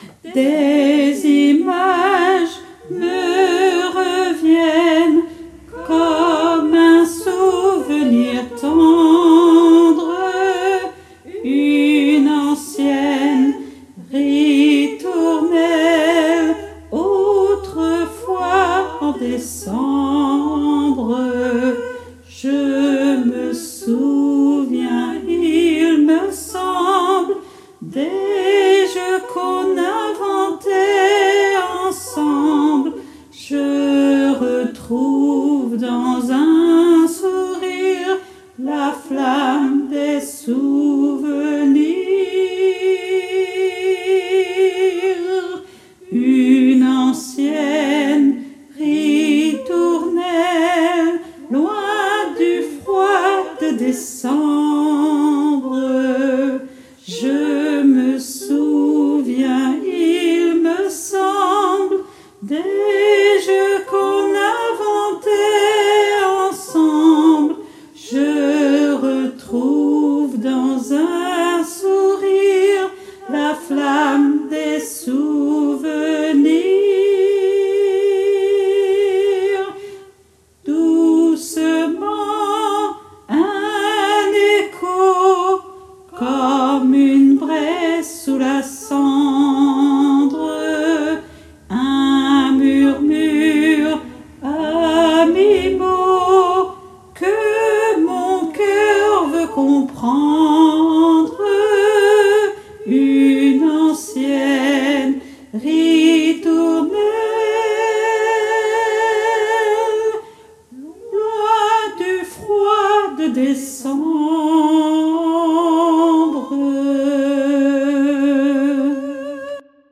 MP3 versions chantées
Alto et autres voix en arrière-plan